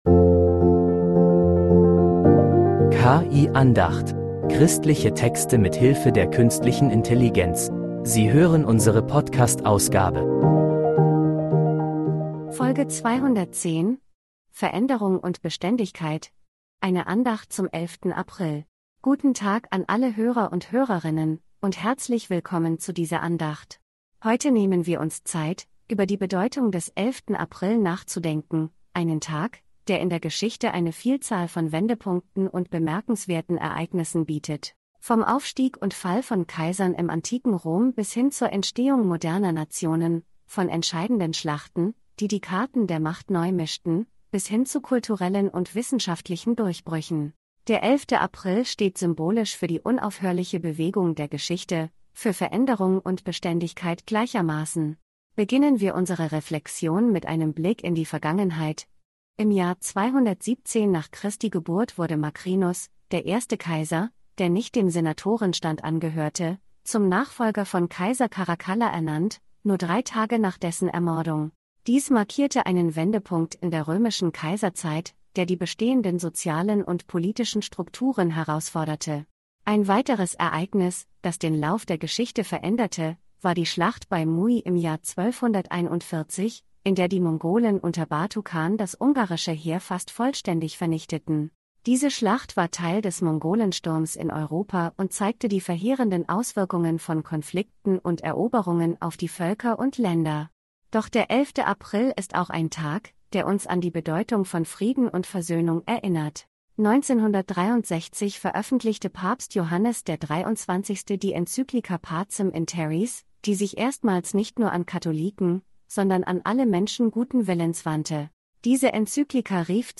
Eine Andacht zum 11. April